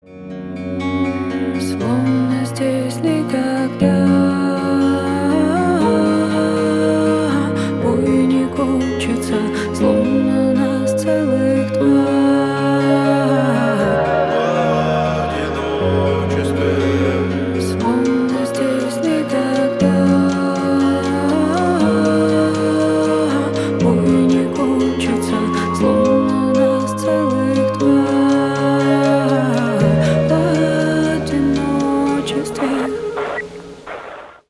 Поп Музыка # Рэп и Хип Хоп
грустные